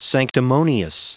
Prononciation du mot sanctimonious en anglais (fichier audio)
Prononciation du mot : sanctimonious